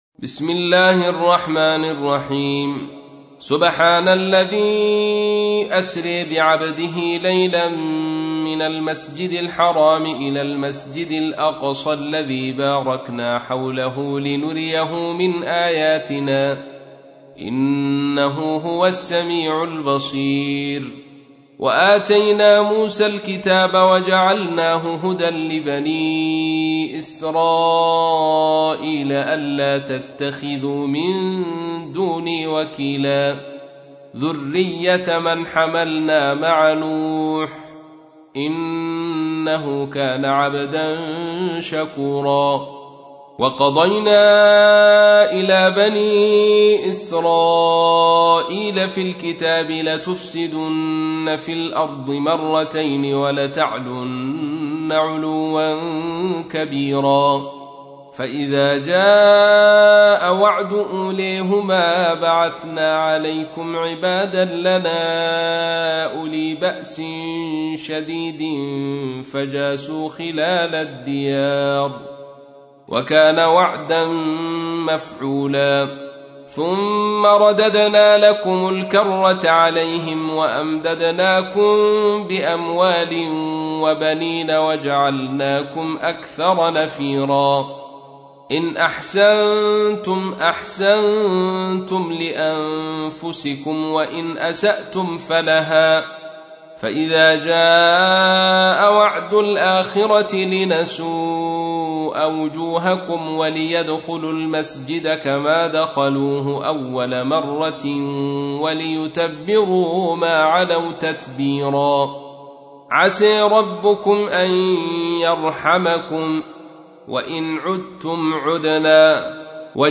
تحميل : 17. سورة الإسراء / القارئ عبد الرشيد صوفي / القرآن الكريم / موقع يا حسين